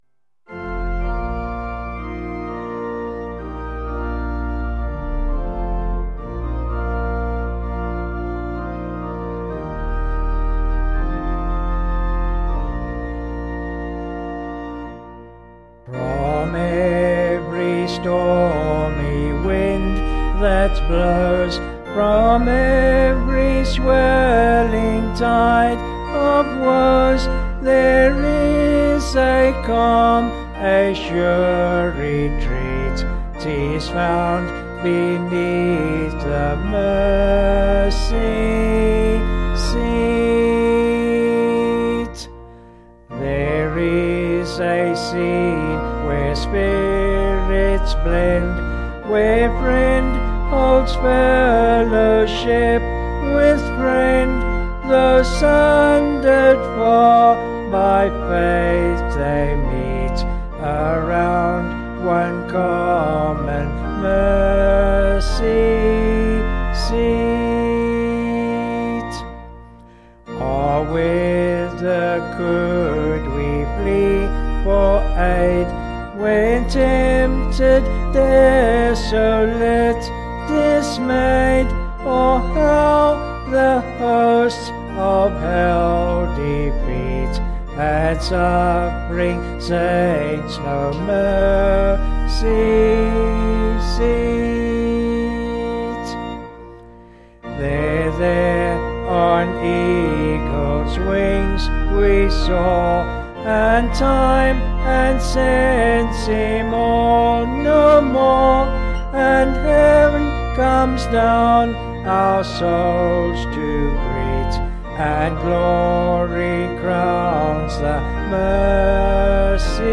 (BH)   4/Bb
Vocals and Organ   263.4kb Sung Lyrics